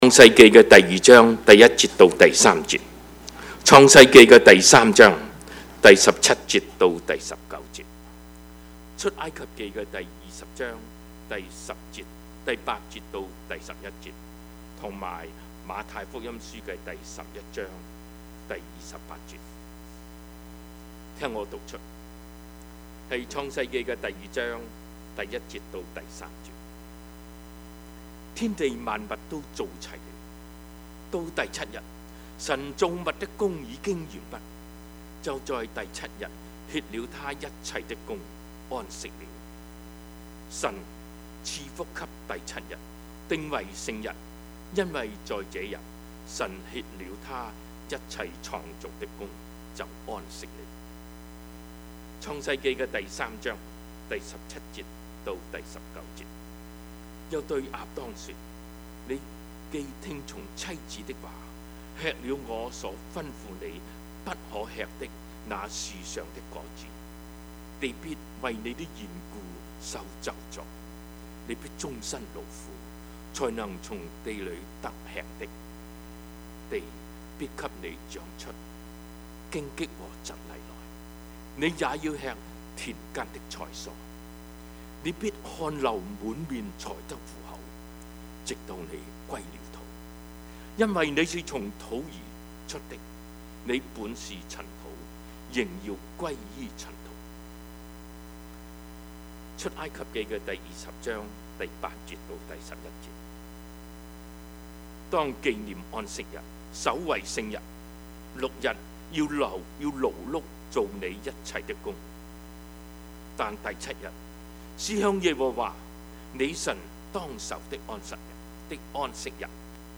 Service Type: 主日崇拜
Topics: 主日證道 « 李鴻章出訪西洋及中俄關係 選戰 »